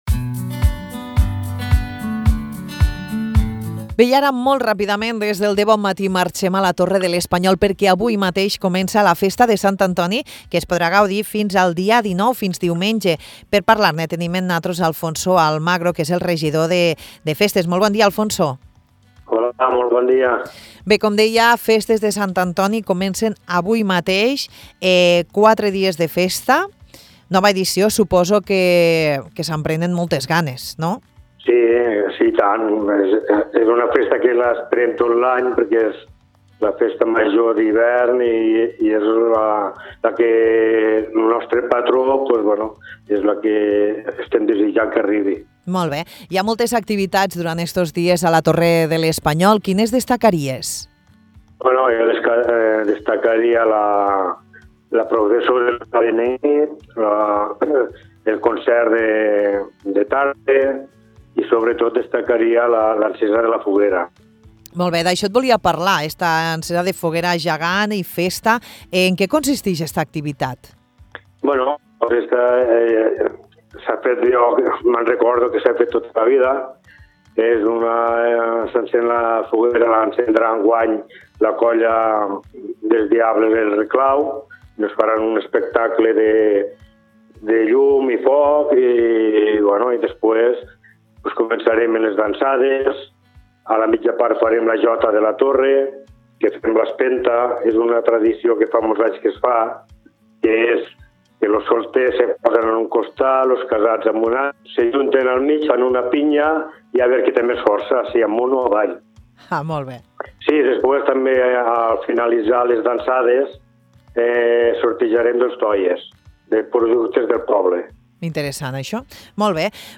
Alfonso Almagro és el regidor de festes de la Torre de l’Espanyol. Avui parlem amb ell sobre la celebració de les Festes de Sant Antoni que tindran lloc al municipi del 16 al 19 de gener.